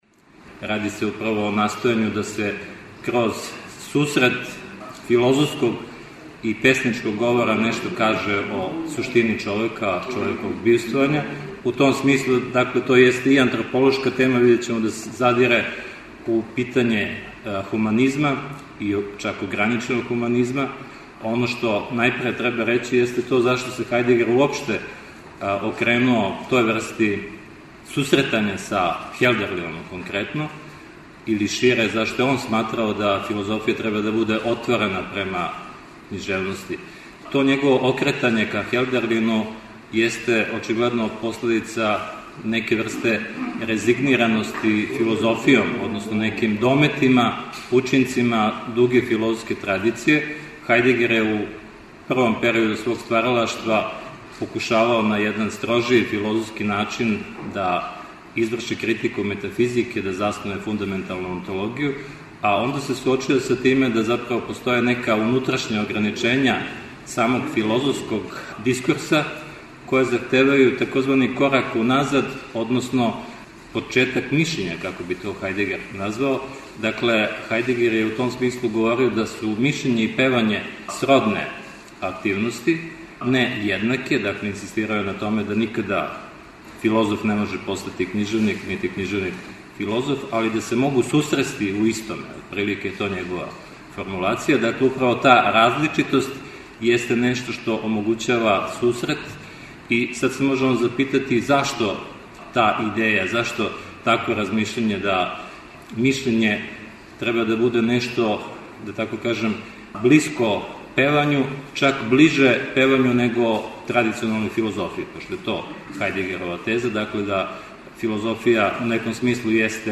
Научни скупови
У оквиру Видовданских свечаности града Крушевца 17. и 18. јуна у Културном центру Крушевац одржана је 27. Крушевачка филозофско-књижевна школа.